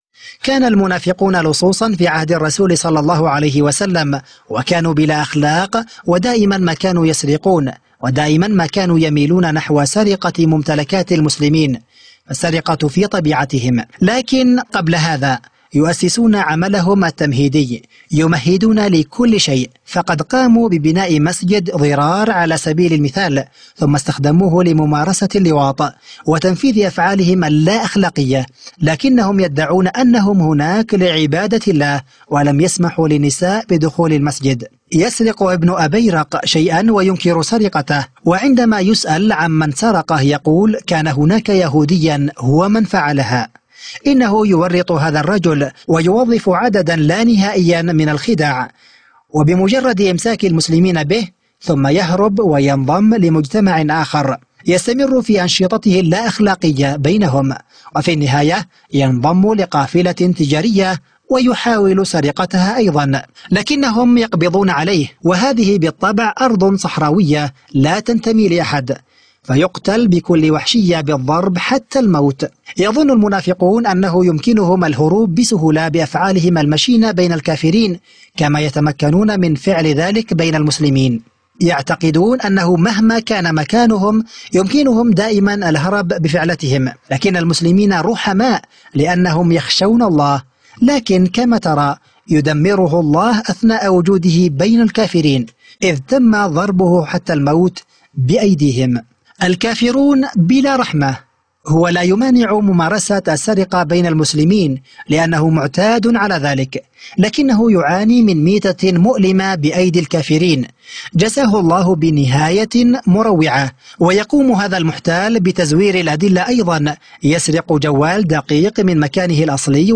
مُقتطف من البث المباشر لحوار عدنان أوكطار على قناة A9TV بتاريخ 8 يونيو 2015 عدنان أوكطار: كان المنافقون لصوصًا في عهد الرسول صلى الله علي...